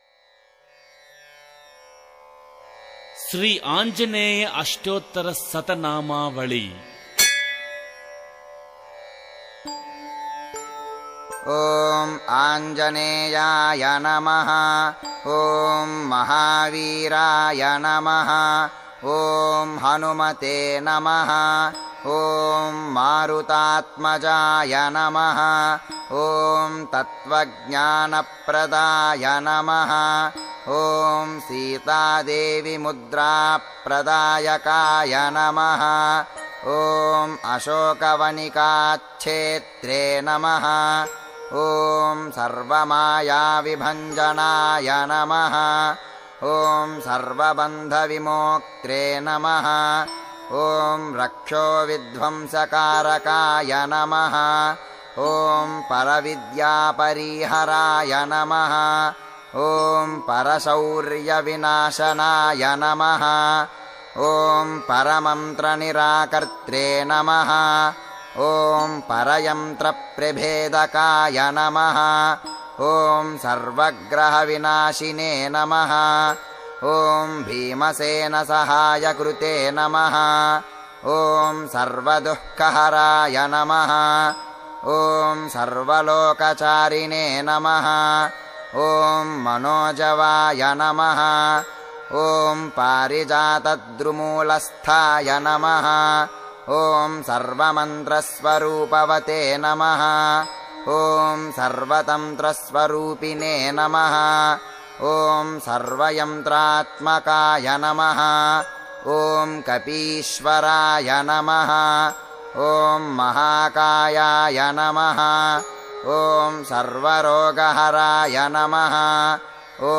Tamil Devotional Songs